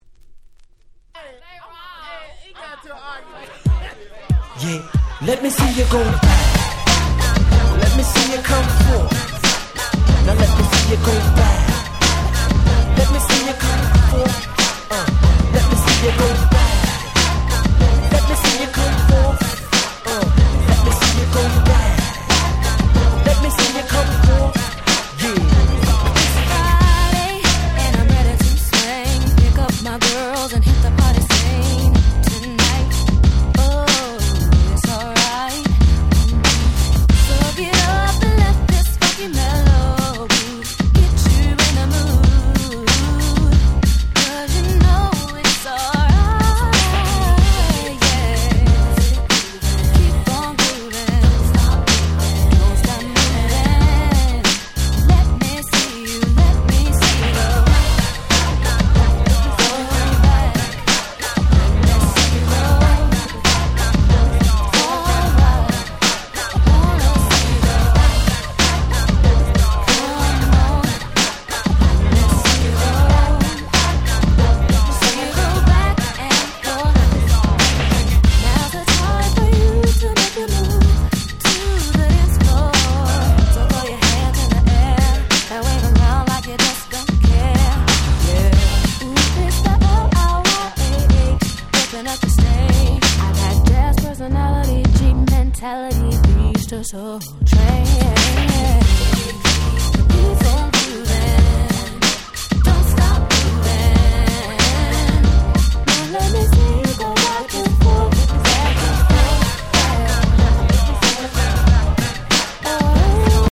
ピーヒャラ具合が大変心地良く、西物好きからも人気の高い1枚！！
イントロを華麗にコスってカットインがCool !!
キャッチー系